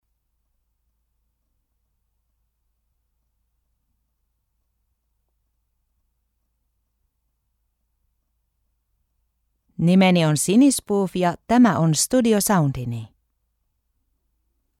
Female
30s, 40s, 50s
Approachable, Character, Confident, Conversational, Corporate, Friendly, Natural, Warm, Versatile
Voice reels
Microphone: Sennheiser MKH 416 P48